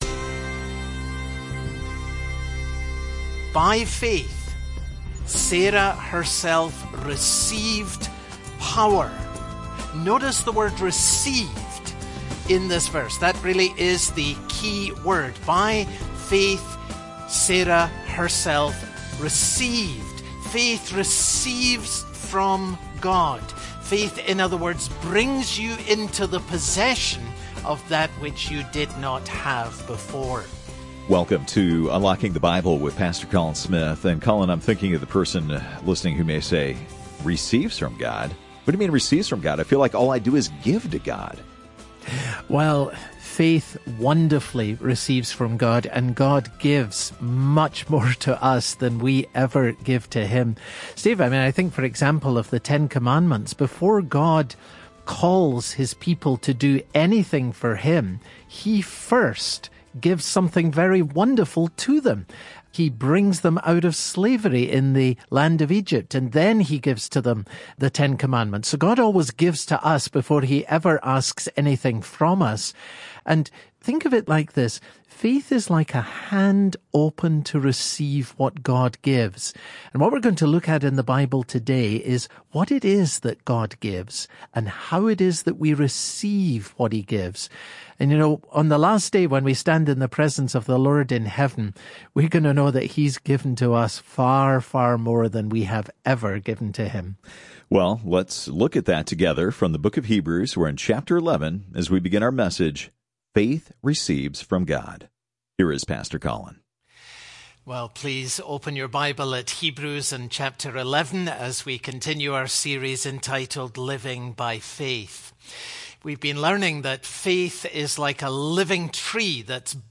Part 1 Hebrews Broadcast Details Date Jan 18